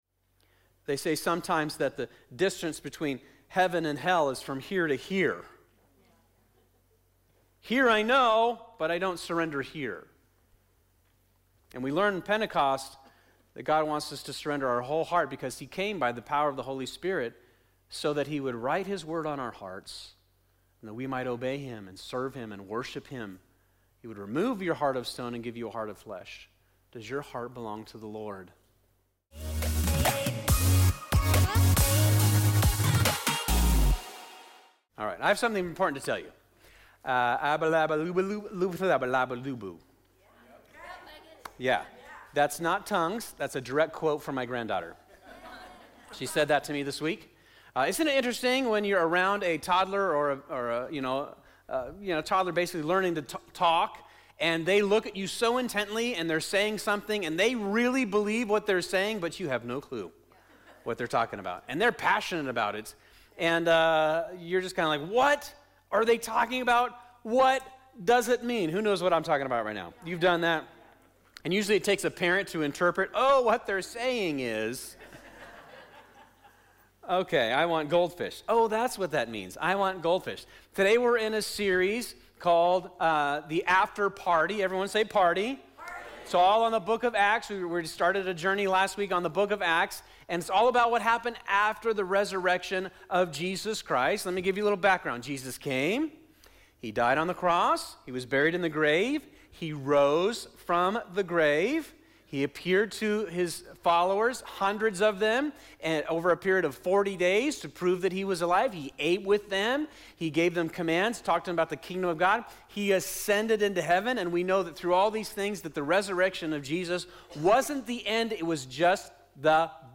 2025 The After Party Curse Freedom Holy Spirit Pentecost Sunday Morning "The After Party" is our series at Fusion Christian Church on the book of Acts.